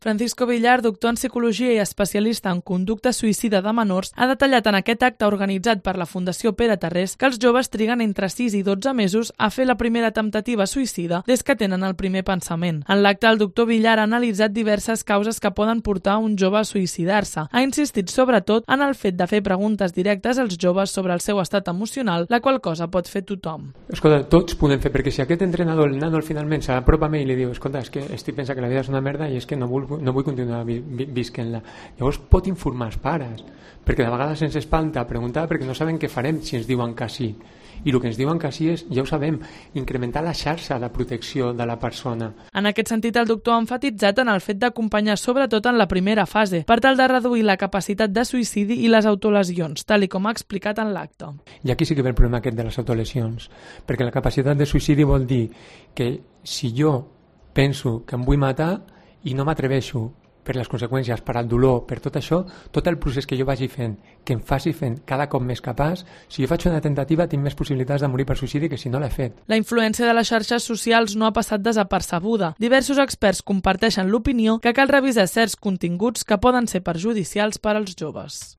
Los suicidios son una de las principales causas de muerte entre los jóvenes - Crónica